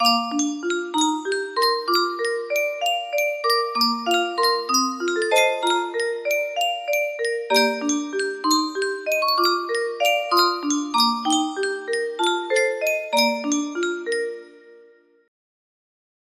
Yunsheng Music Box - Now Is the Hour 5885 music box melody
Full range 60